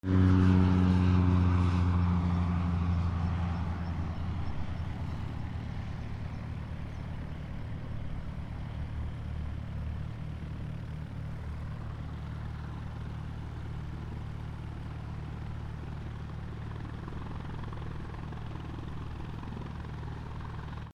飛行機 プロペラ機 離陸
/ E｜乗り物 / E-80 ｜飛行機・空港